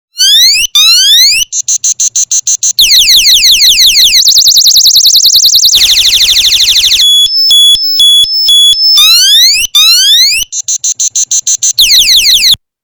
alarm01.MP3